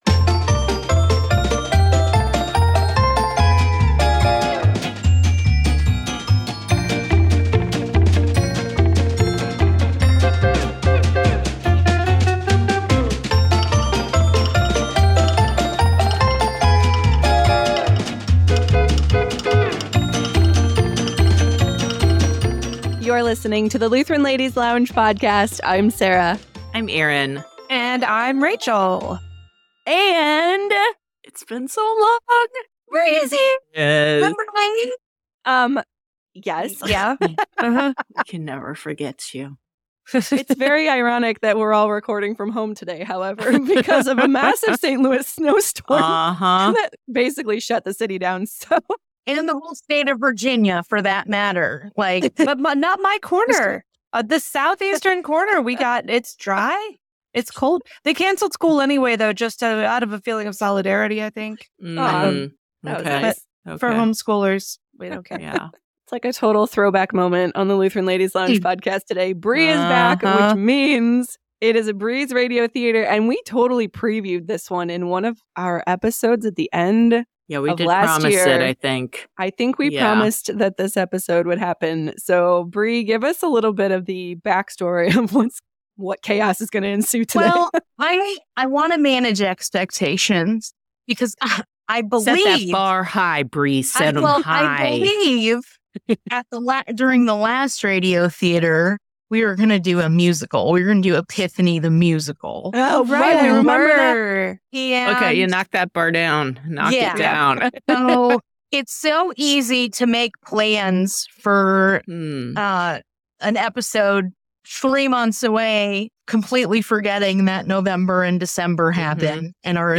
Disclaimer: This episode is satire, Trish is a fictional character, and we already know our script probably wouldn’t pass LCMS Doctrinal Review on the first try.